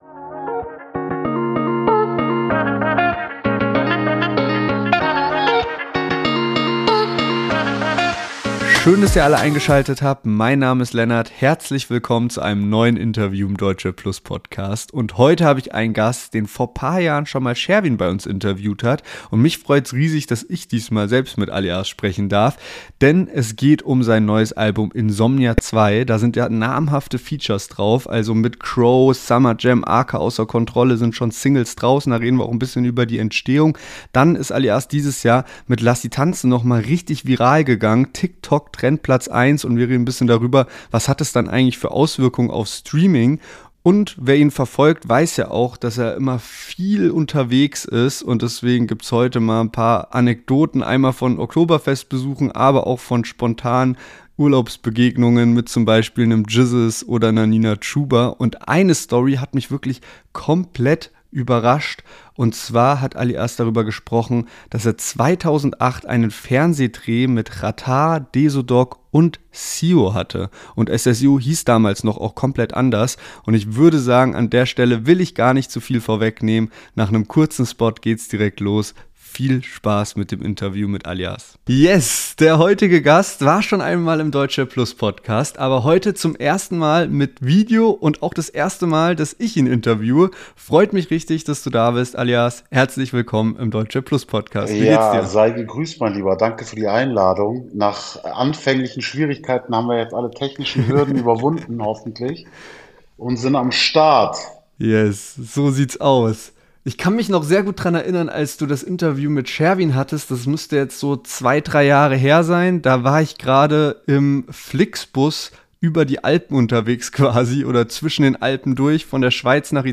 Freut euch auf einen spannenden Deutschrap-Talk mit Ali As und vielen Anektoden!